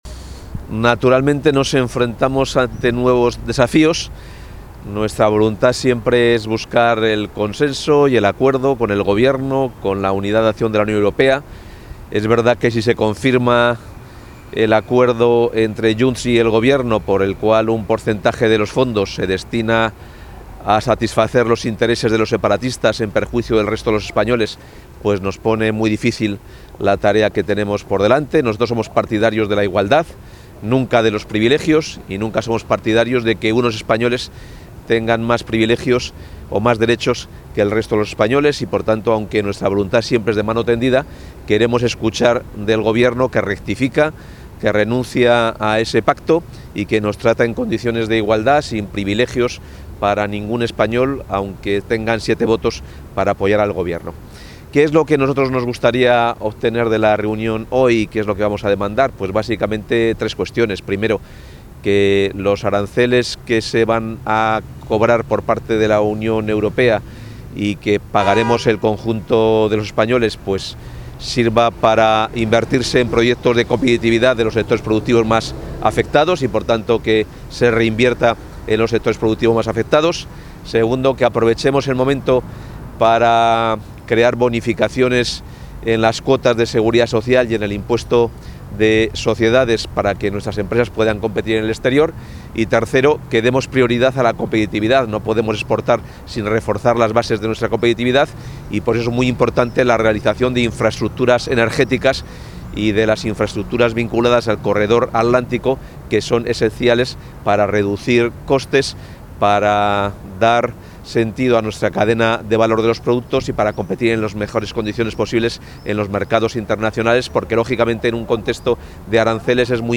Declaraciones del consejero de Economía y Hacienda previas a la reunión del Consejo Interterritorial de Internacionalización
El consejero de Economía y Hacienda, Carlos Fernández Carriedo, asiste hoy al Consejo Interterritorial de Internacionalización, convocado para analizar el plan de respuesta frente a la aplicación de aranceles por EEUU. A su llegada a la sede del Ministerio de Economía, Comercio y Empresa en Madrid, el también portavoz de la Junta ha realizado unas declaraciones previas.